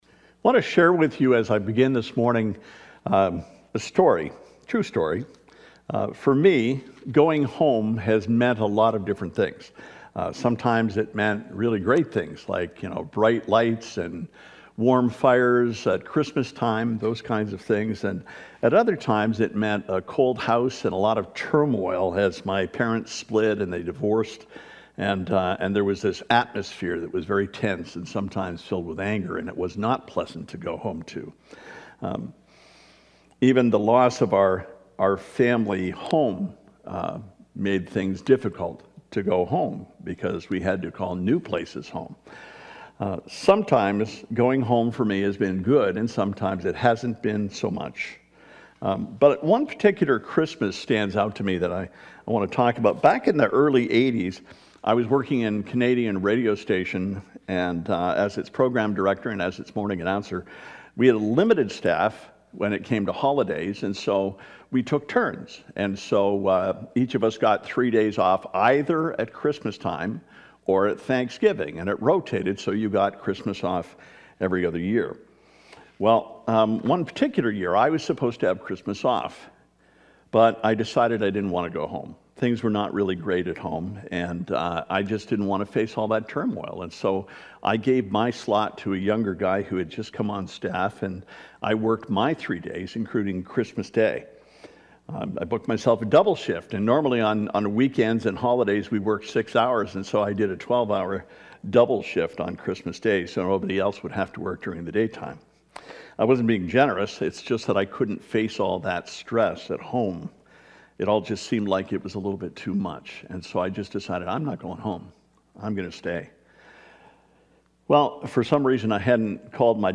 Sermon: Welcome Home Luke 15:11-32